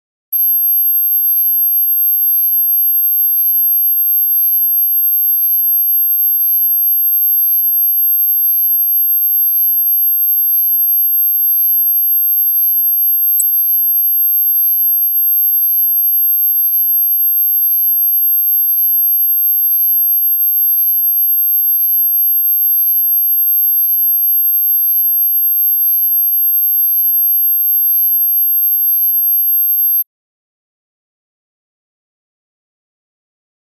Звуки взрыва динамита
Звук звона в ушах после взрыва